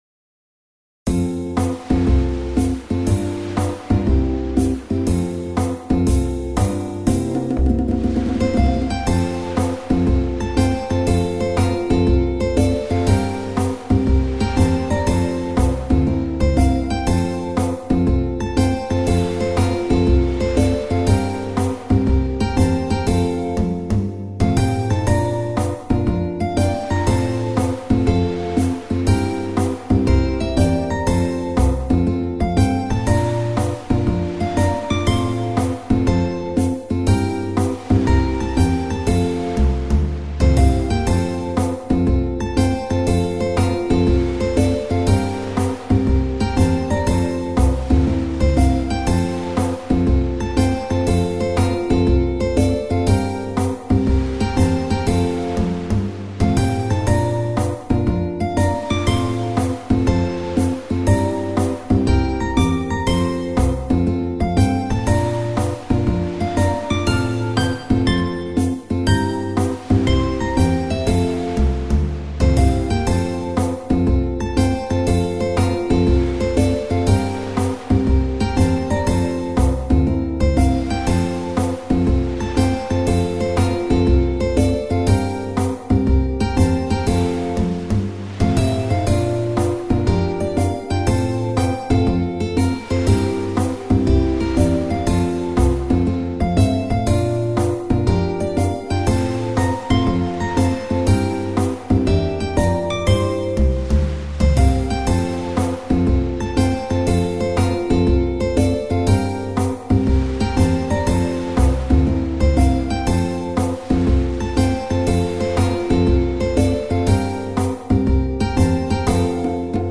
カントリースローテンポ穏やか